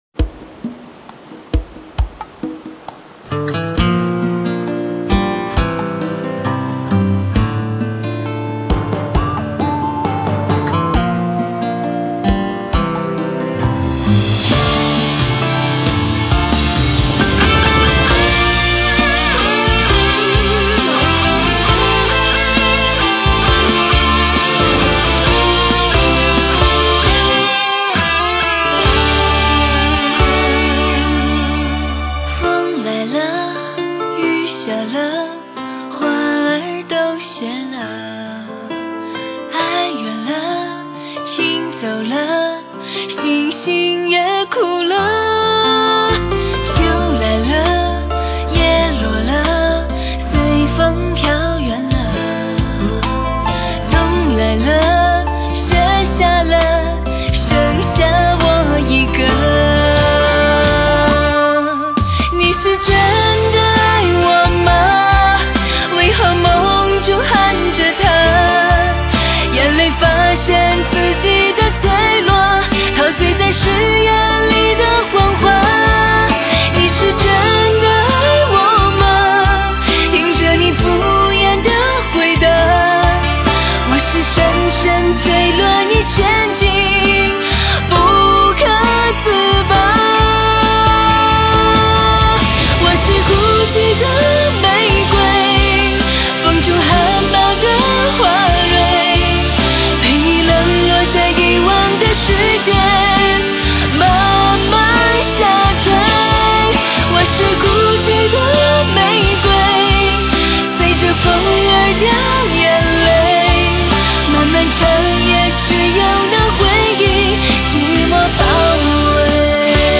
Surroumd 7.1德国技术HDSTS三维高临场音效，真空管高清录制。